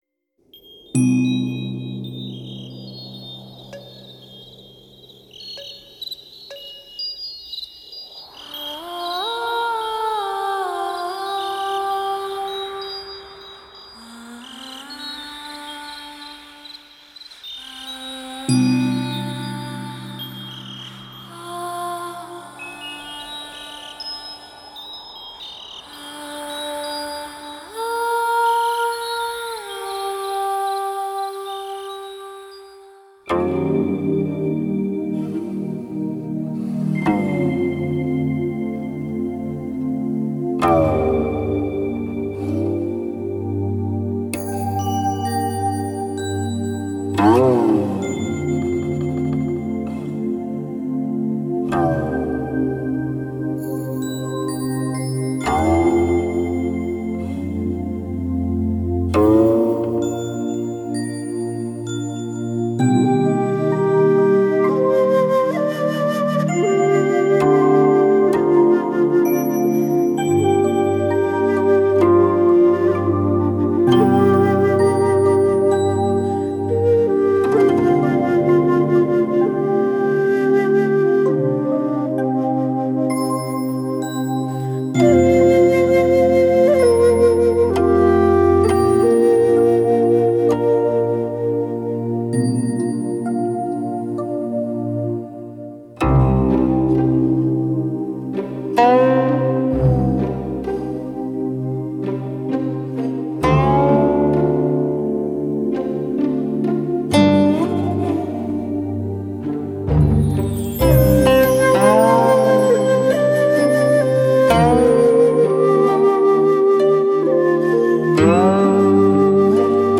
2周前 纯音乐 8